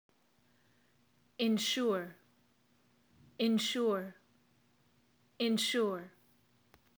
Read on for definitions, example sentences, and mp3s for pronunciation of three easily confused English verbs: assure, insure, and ensure.